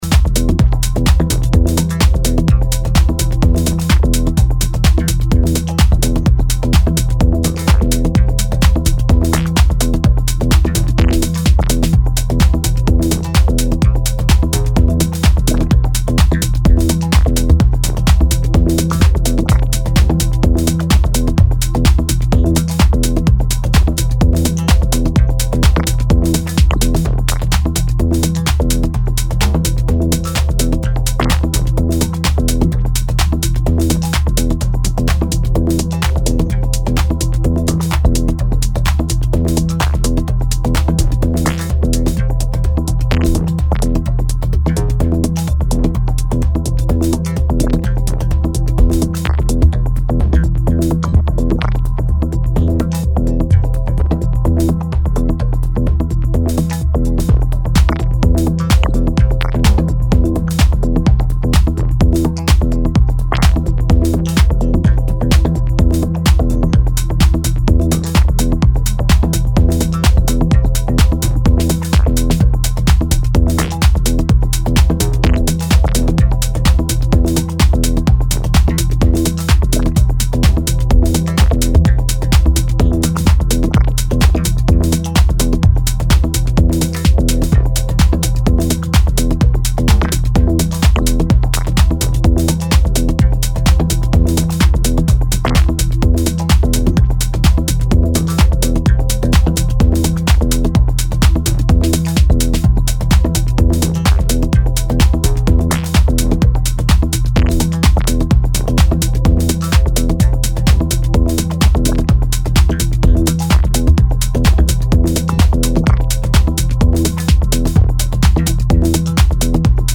presenting a warm and spacey roller.
punchy beats and seeping harmonic flow